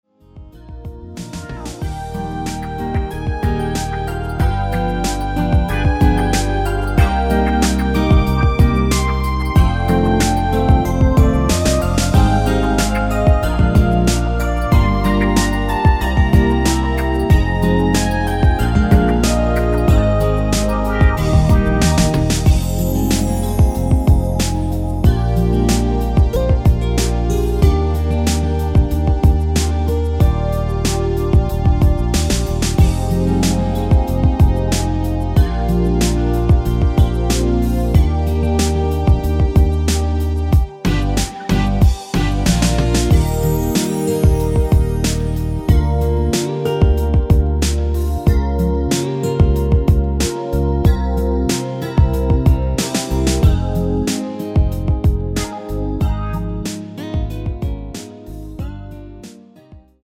랩 부분을 삭제하고 바로 다음으로 연결 되게 만들었습니다.
Eb
앞부분30초, 뒷부분30초씩 편집해서 올려 드리고 있습니다.
중간에 음이 끈어지고 다시 나오는 이유는